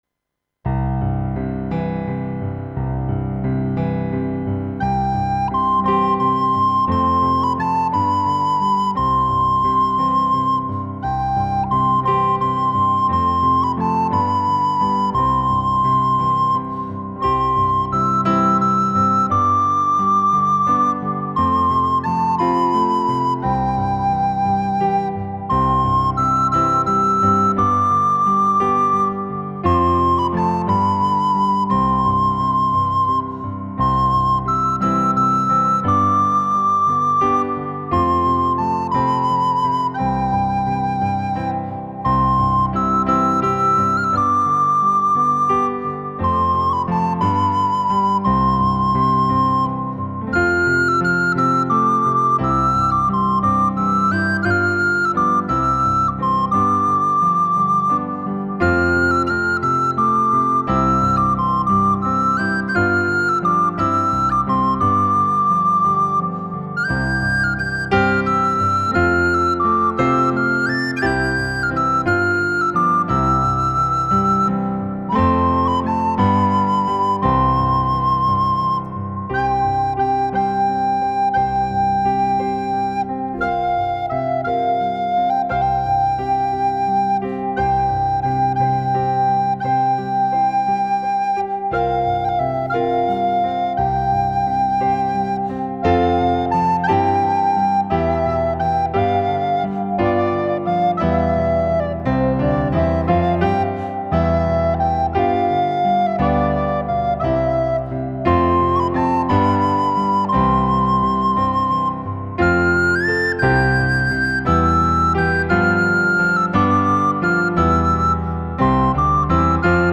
ایرانی